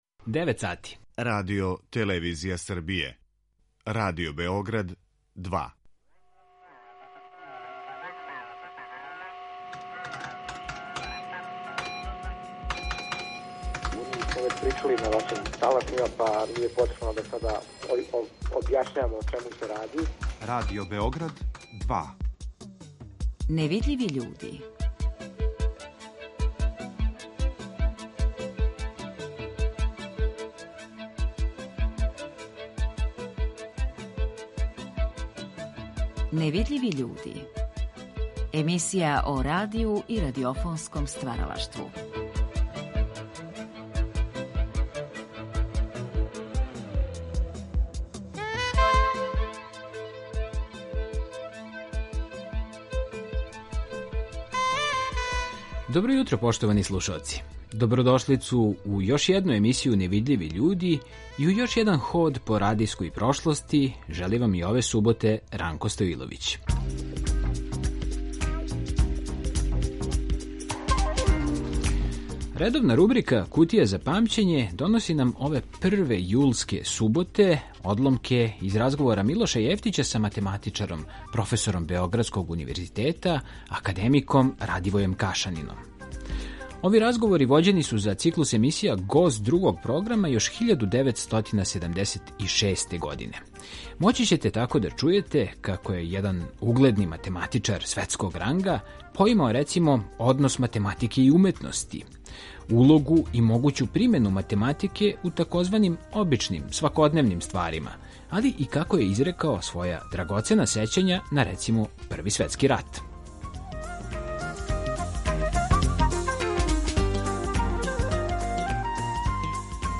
Емисија о радију и радиофонском стваралаштву
Ови разговори вођени су за циклус емисија Гост Другог програма 1976. године.